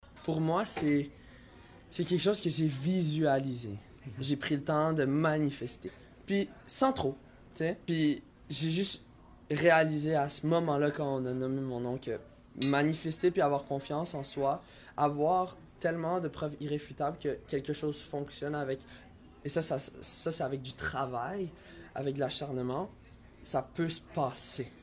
Hier soir, c’était la grande finale de la 57e édition du Festival international de la chanson de Granby, où 5 artistes se sont partagé la scène du Palace de Granby.